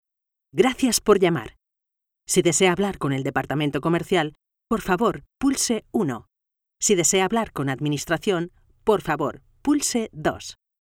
I have a versatile voice that can make different voices, and very different intentions in the same voice, if necessary.
Sprechprobe: Sonstiges (Muttersprache):
My recording equipment is: Neumann condenser microphone TLM 102, Previous Fucusrite Scarlet 2/2 and Software logic pro X and Audition.